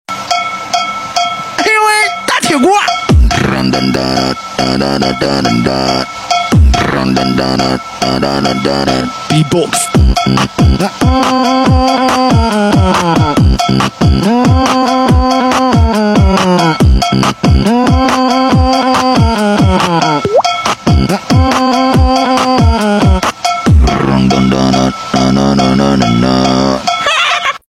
beatbox pan x Cristiano Ronaldo